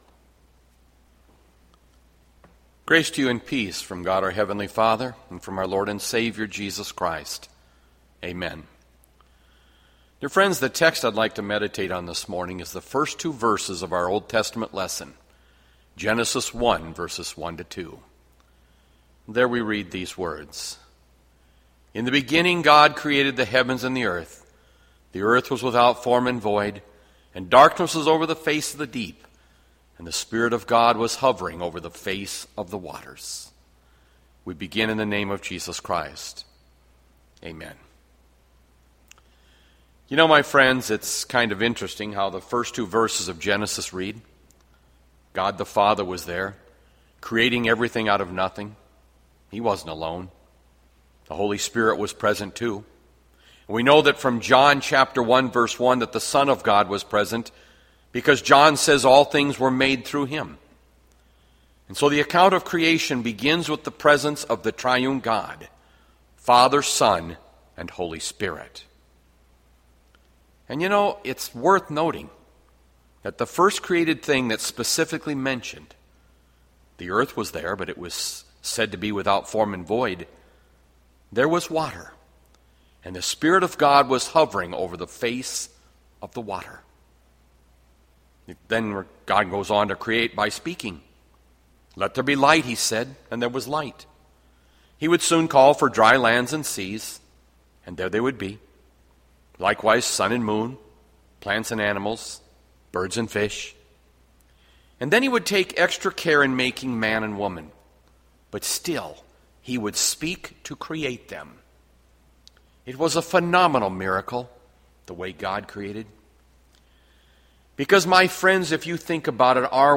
Bethlehem Lutheran Church, Mason City, Iowa - Sermon Archive Jun 7, 2020